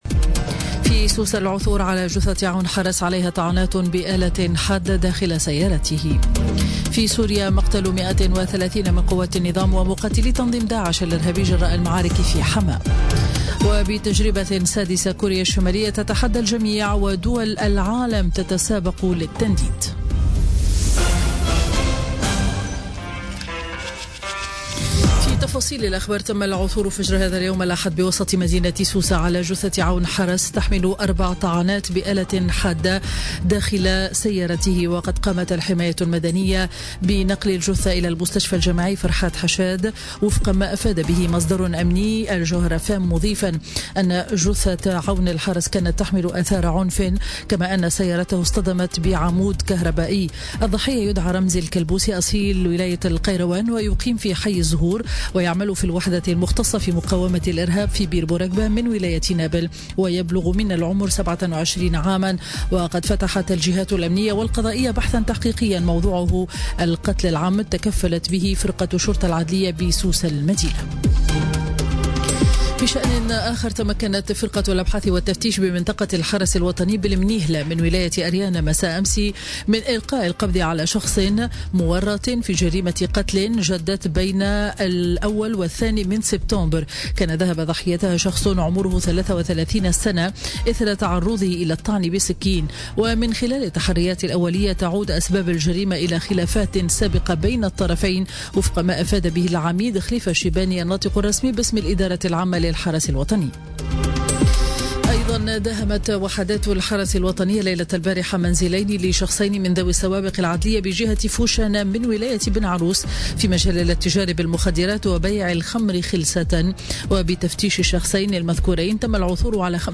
نشرة أخبار منتصف النهار ليوم الأحد 3 سبتمبر 2017